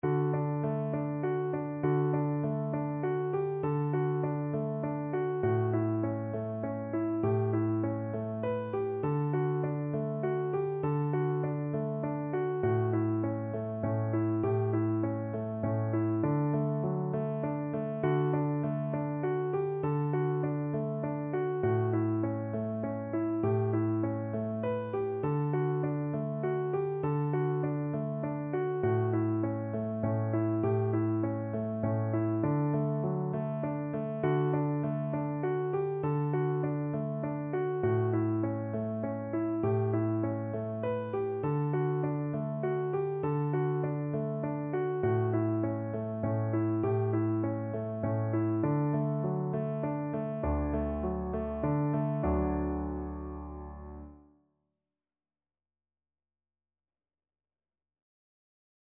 D major (Sounding Pitch) (View more D major Music for Voice )
Gently flowing